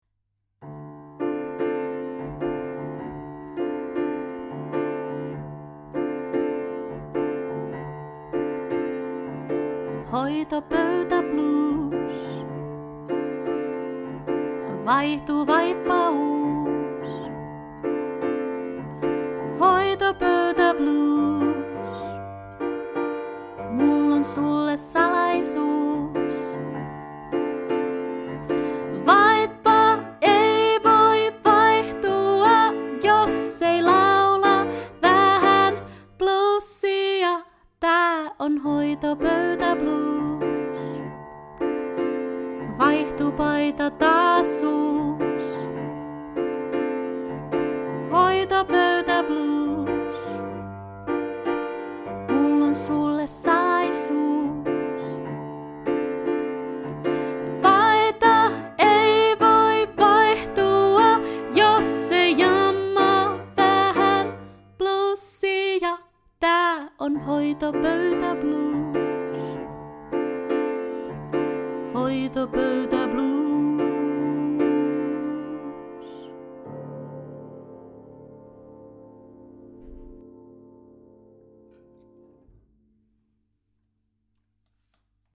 Kappaleista löytyy rauhallisia lauluja hellittelyhetkiin vauvan kanssa sekä reipastempoisia lauluja aktiivisiin leikkihetkiin ja itkun tyynnyttelyyn.
Olen äänittänyt sävellykset laulaen ja pianolla itseäni säestäen.